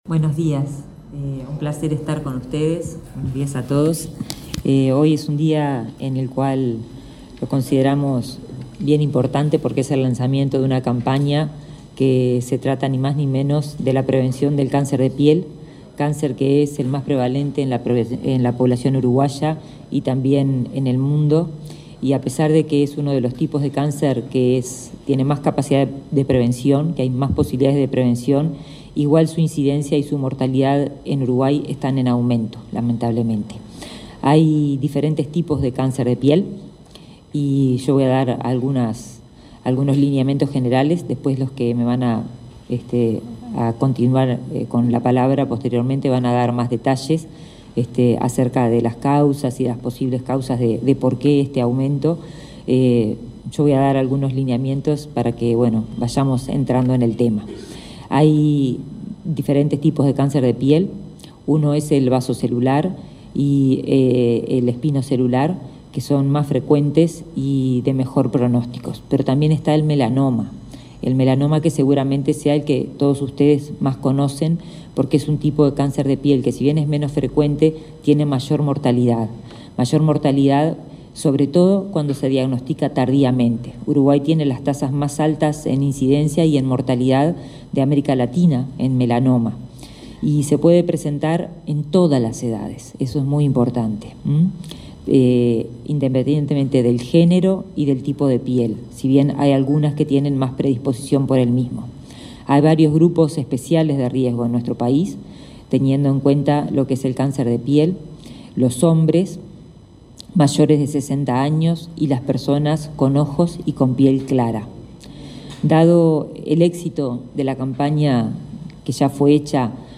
Palabras de autoridades en acto en el Ministerio de Salud Pública